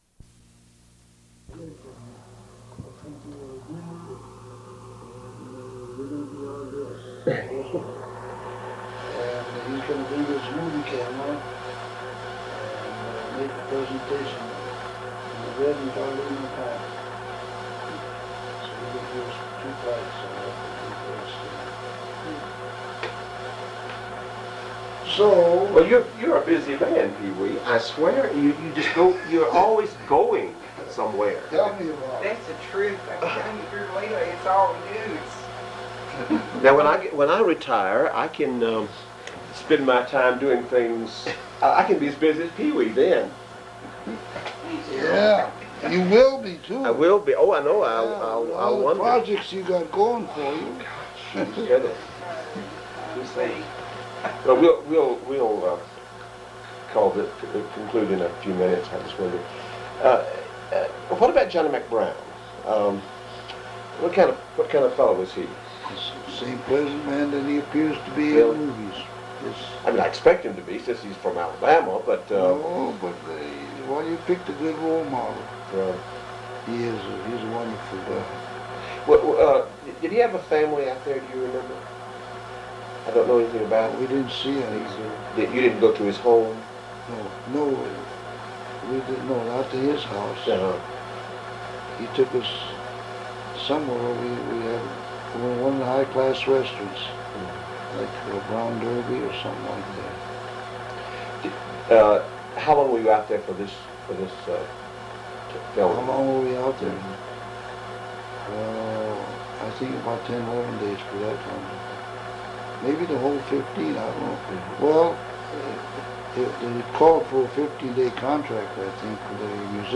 Oral History Interview with Pee Wee King